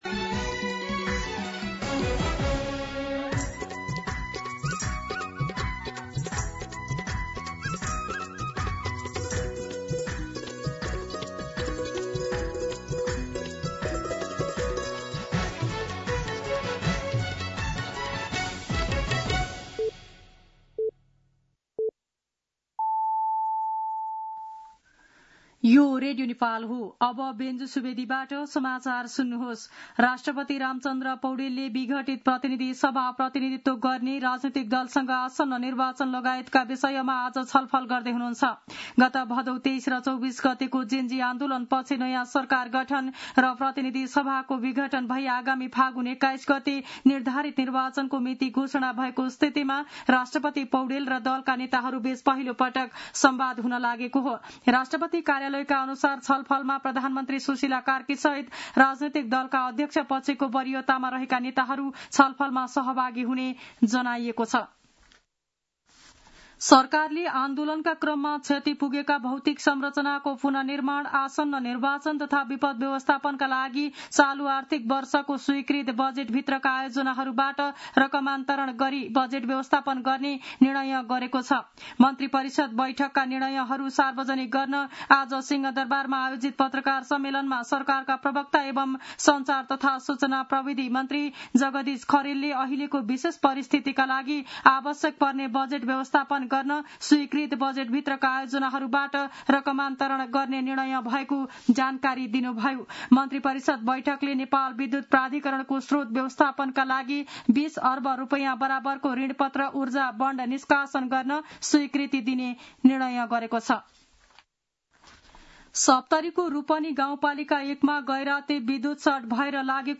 दिउँसो ४ बजेको नेपाली समाचार : २४ असोज , २०८२